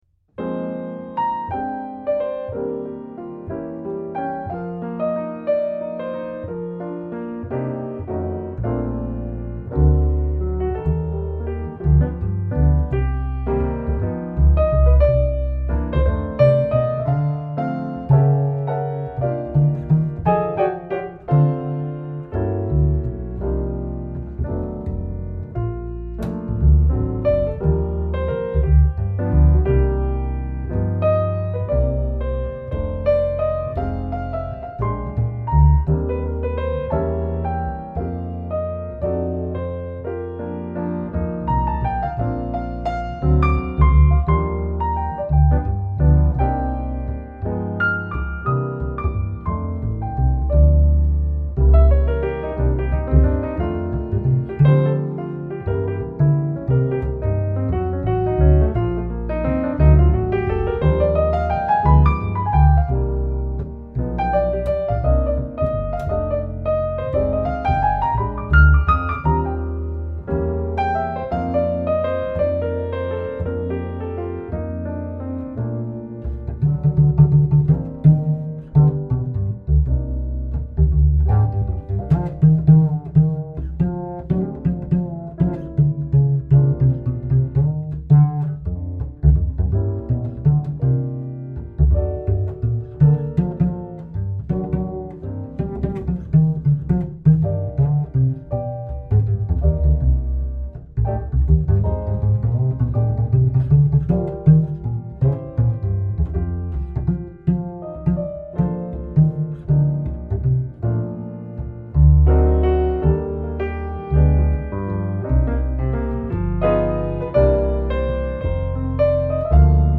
Piano, Double Bass